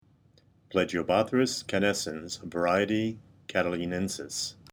Pronunciation/Pronunciación:
Pla-gi-o-bó-thrys ca-nés-cens var. ca-ta-li-nén-sis